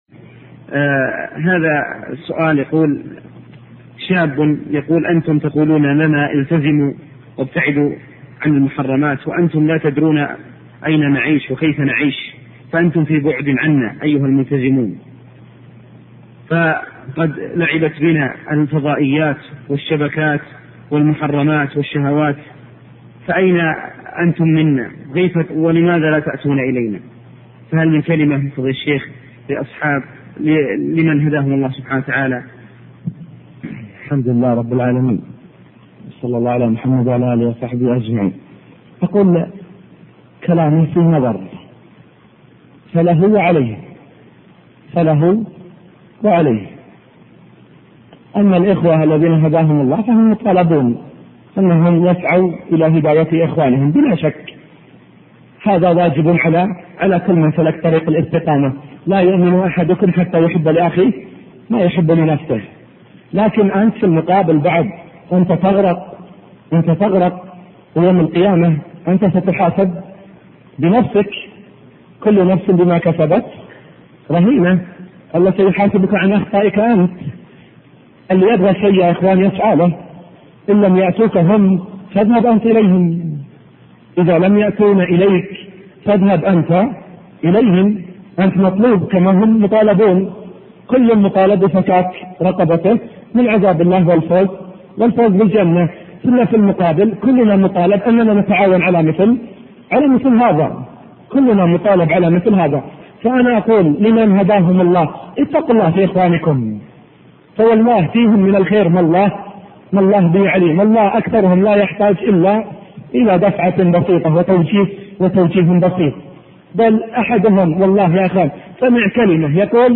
سؤال وجواب